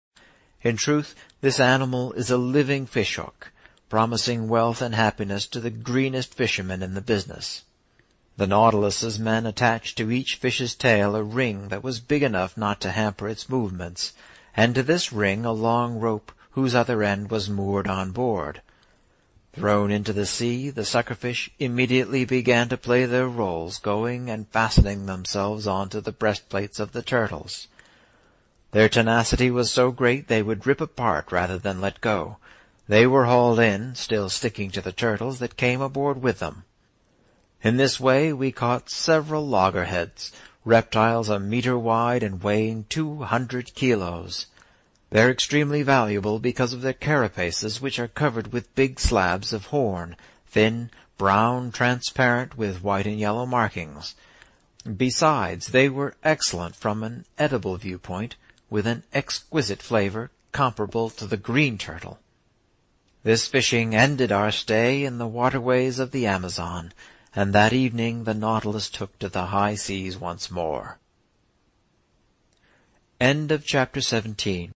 在线英语听力室英语听书《海底两万里》第492期 第30章 从合恩角到亚马逊河(15)的听力文件下载,《海底两万里》中英双语有声读物附MP3下载